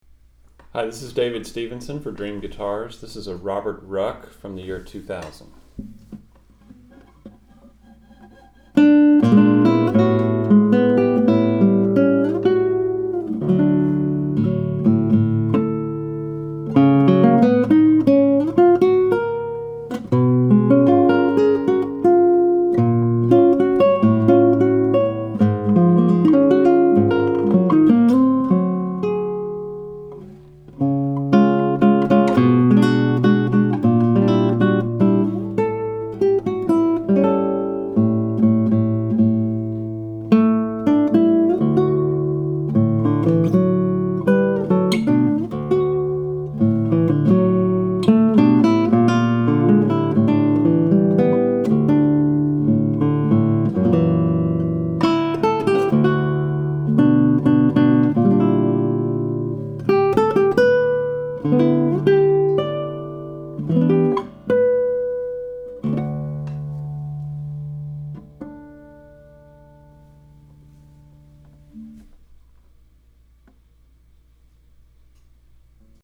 2000 Ruck Classical Indian/Cedar - Dream Guitars
Here’s an exquisite Robert Ruck Concert guitar crafted in the year 2000. It features several of his innovative features including dual soundports and an elevated fingerboard.
DS-ruck_classical_758.mp3